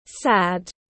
Buồn tiếng anh gọi là sad, phiên âm tiếng anh đọc là /sæd/
Sad /sæd/